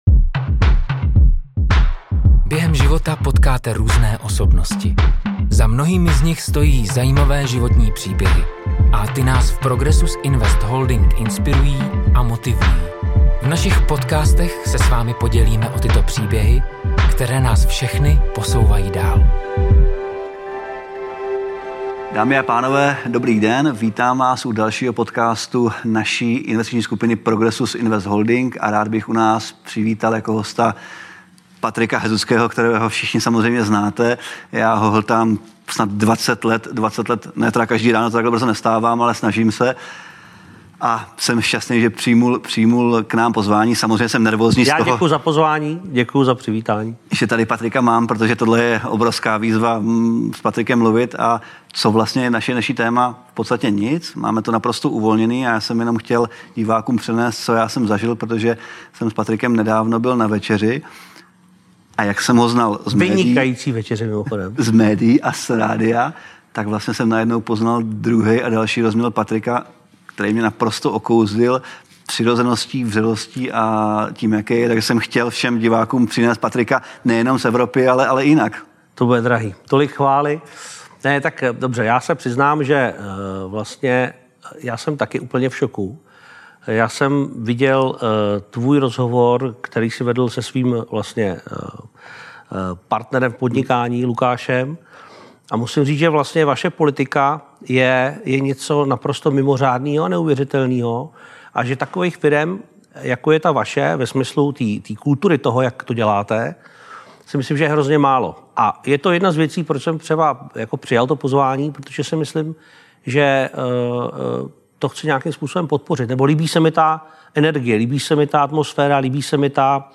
Opravdu pracuje jen 3 hodiny denně? Poslouchá vůbec rádio? A co dělá v létě, když má prázdniny? Velmi příjemný rozhovor, který jistě nebyl posledním.